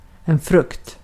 Ääntäminen
Ääntäminen Tuntematon aksentti: IPA: /ˈfrɵkt/ Haettu sana löytyi näillä lähdekielillä: ruotsi Käännös Konteksti Substantiivit 1. hedelmä kasvitiede 2. tulos 3. tuotos Artikkeli: en .